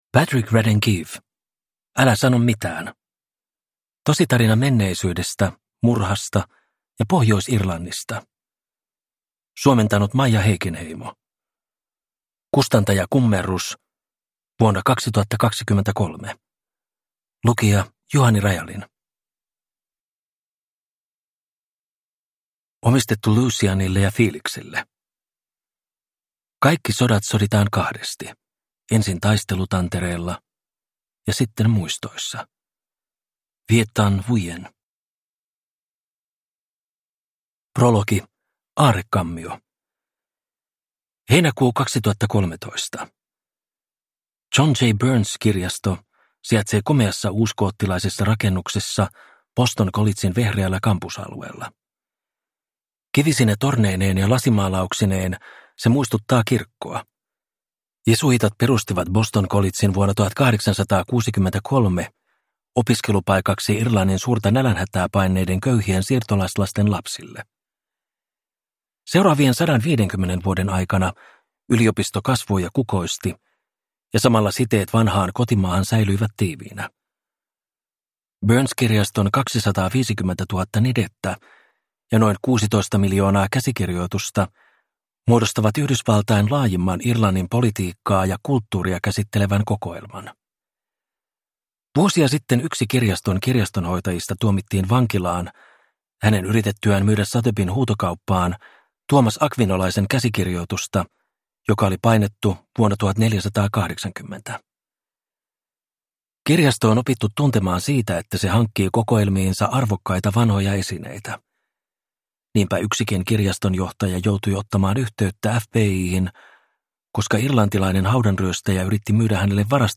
Älä sano mitään – Ljudbok